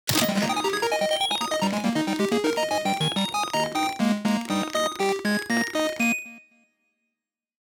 bonus-shuffling.aac